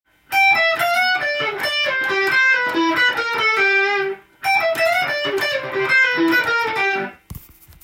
譜面通り弾いてみました
コードがDの時に使われているものなので
Dのミクソリディアン的な雰囲気で弾いています。